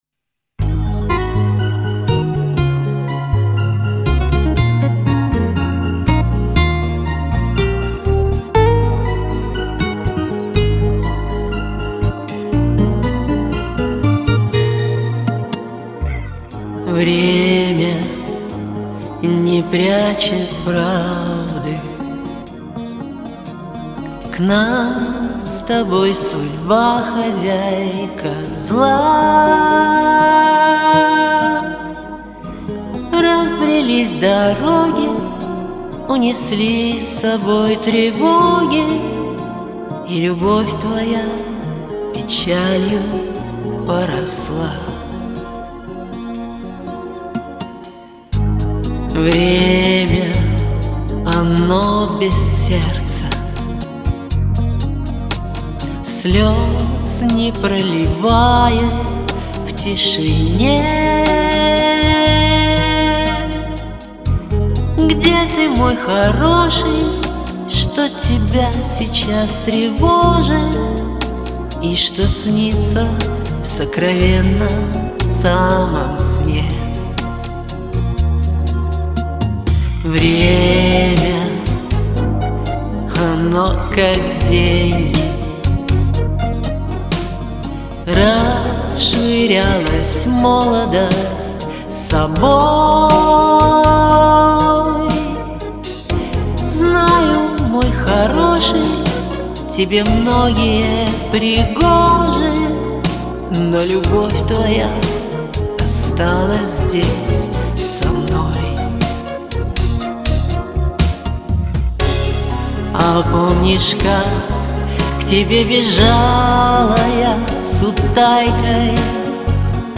Как же ты здорово тут звучишьklass